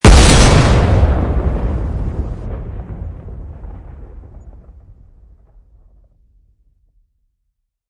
Shotgun 03 Sound Button - Free Download & Play
Sound Effects Soundboard3,550 views